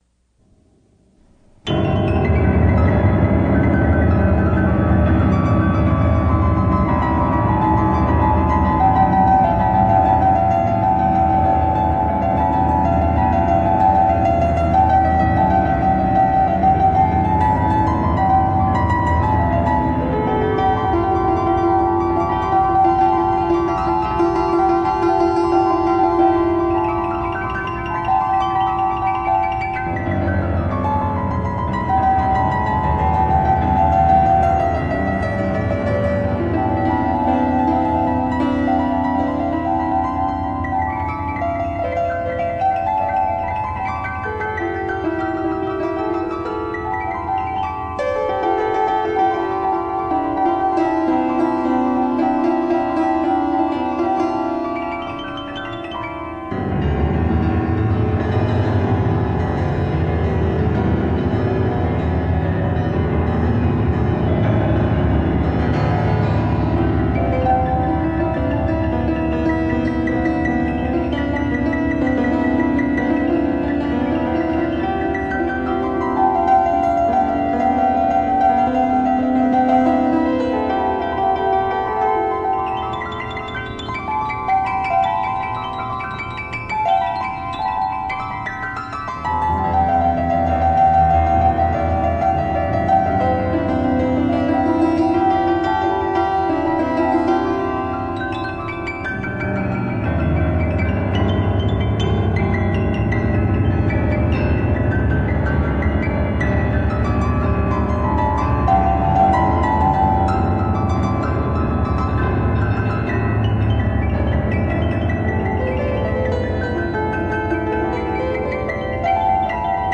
This album includes two piano recordings from 1991 and 1992